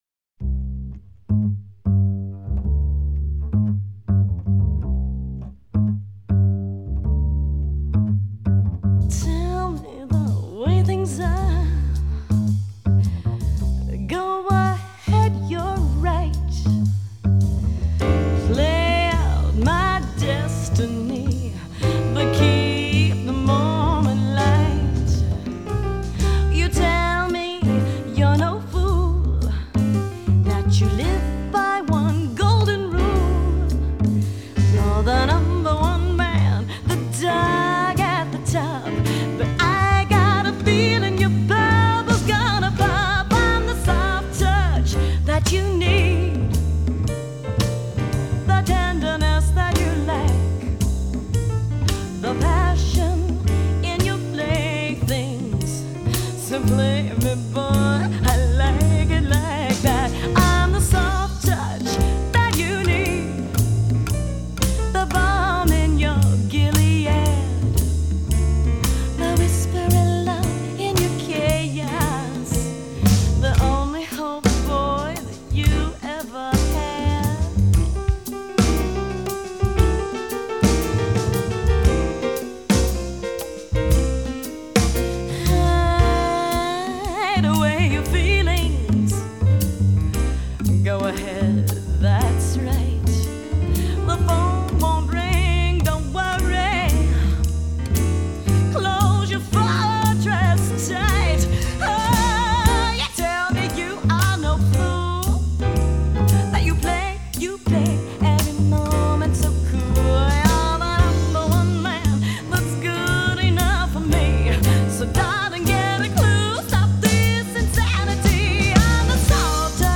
The Joyful Side of Jazz,
Soul and Gospel Music!